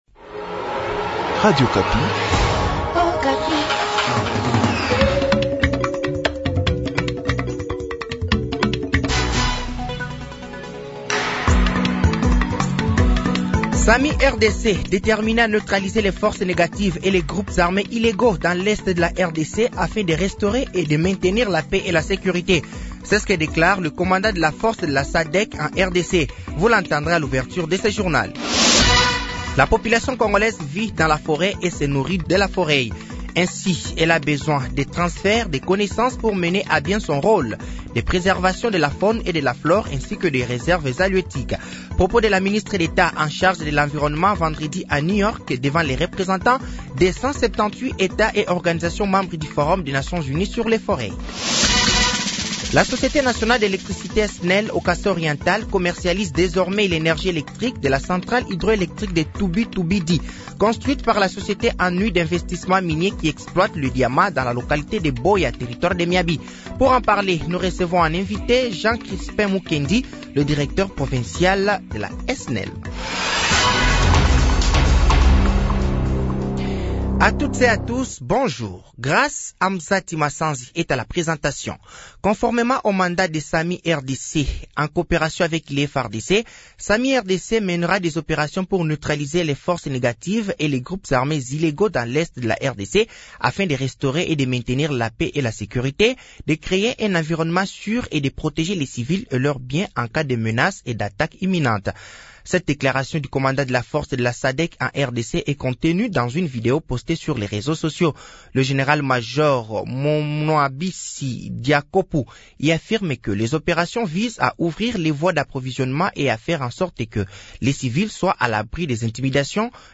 Journal français de 7h de ce dimanche 12 mai 2024